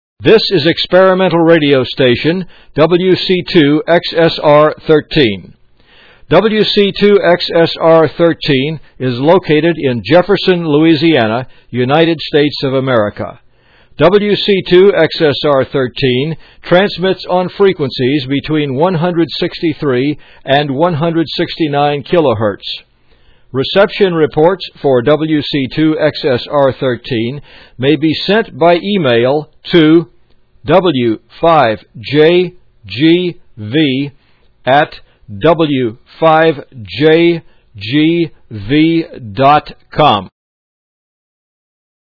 You can also hear what the audio ID loop sounds like as it is sent to the transmitter by listening to this
Of course, the on-the-air signal is much reduced in frequency response because of the bandwidth of the audio circuits in the exciter and the narrow bandwidth of the transmitting antenna.
The audio has a moderate amount of compression.
wc2xsr13-ssb-id.mp3